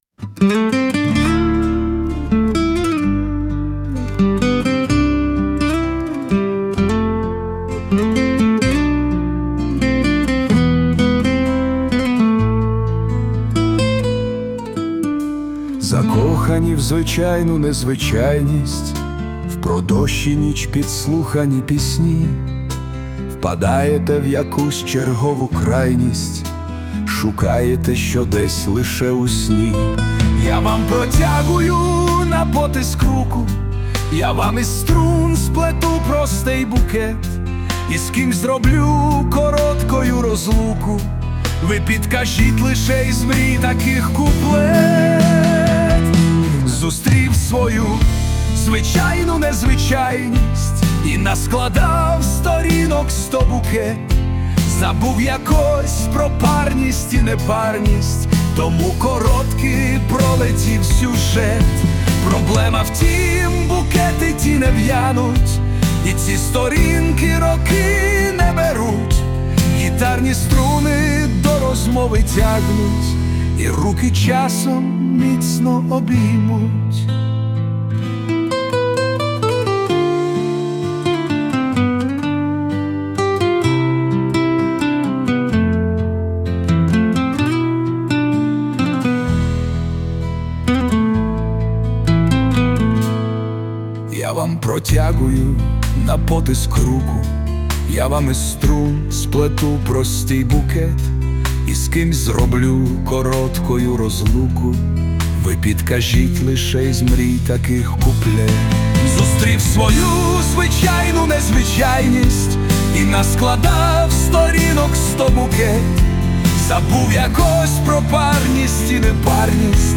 У виконанні присутня допомога SUNO
СТИЛЬОВІ ЖАНРИ: Ліричний